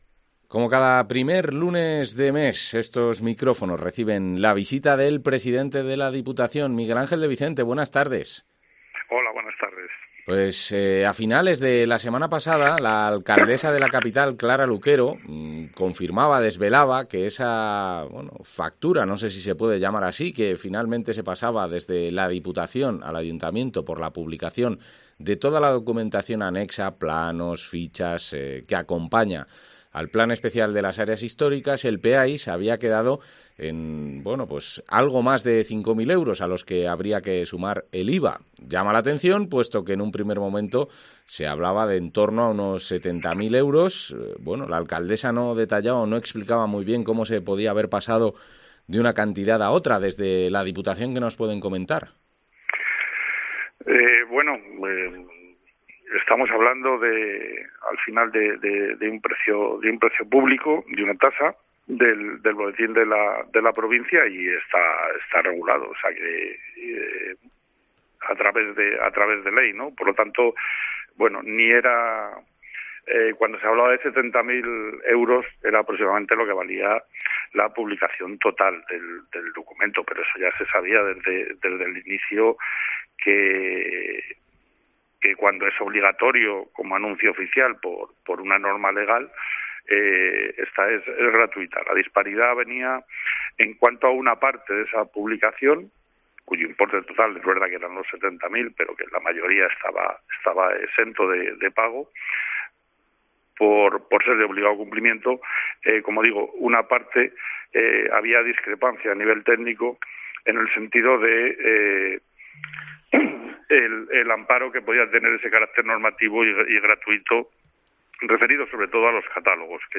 Entrevista al presidente de la Diputación, Miguel Ángel de Vicente